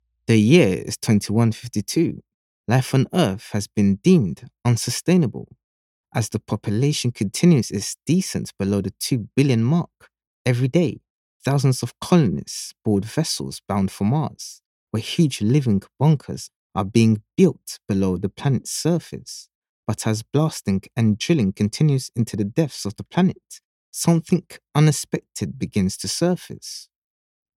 Ballsy Movie Trailer Voice Over Actors | Movie Promo Voice Over Guy
English (Caribbean)
Adult (30-50) | Yng Adult (18-29)
Looking for deep, ballsy voice over talent for your next Hollywood blockbuster?